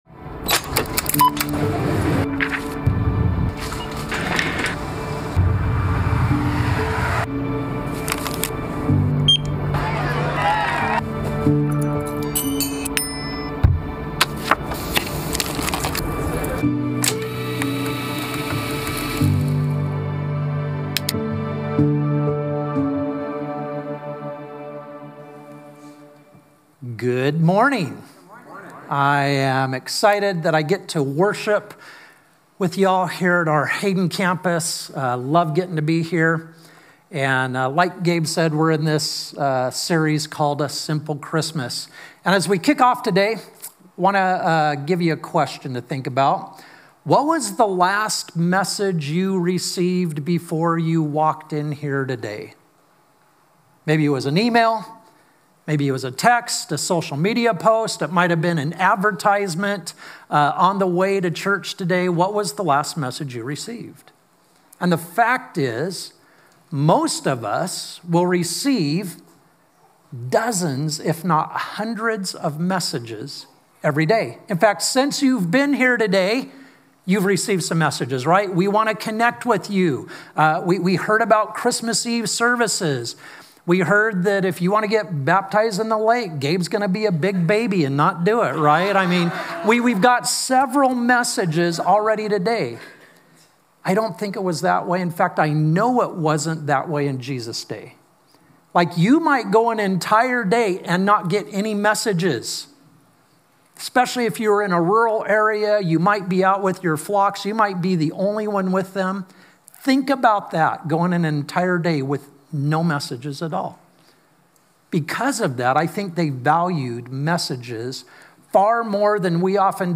Simple Christmas - Week Three // A Simple Message // Hayden Campus
Sermon